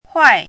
“坏”读音
坏字注音：ㄏㄨㄞˋ
国际音标：xuai˥˧
huài.mp3